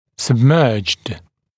[səb’mɜːʤd][сэб’мё:джд]погружённый